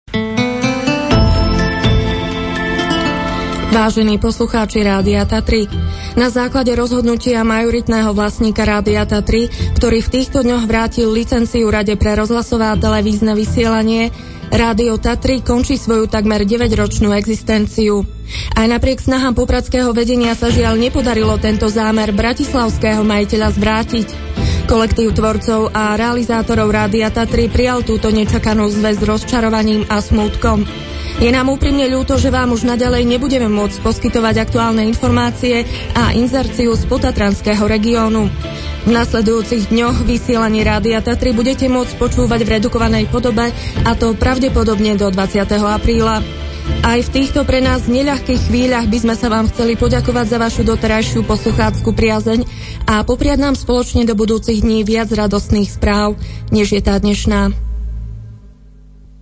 oznam o konci vysielania púšťaný vždy po celej hodine.